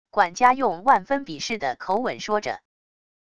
管家用万分鄙视的口吻说着wav音频